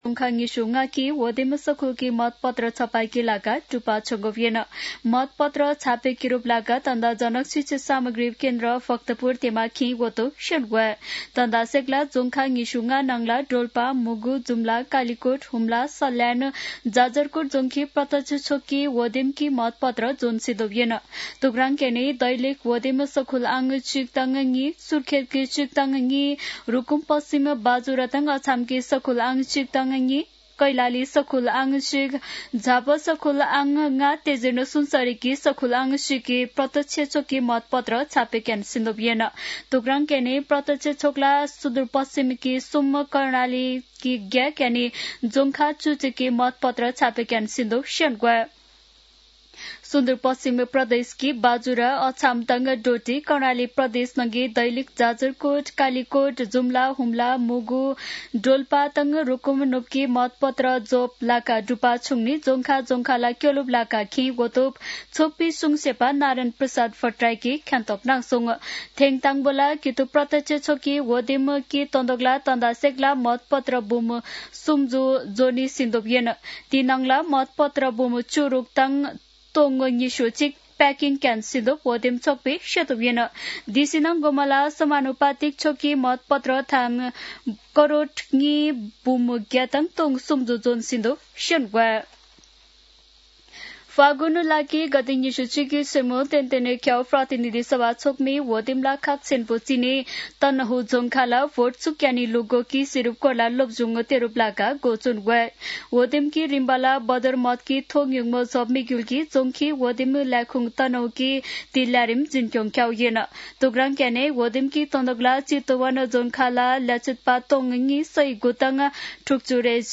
शेर्पा भाषाको समाचार : १८ माघ , २०८२
Sherpa-News-18.mp3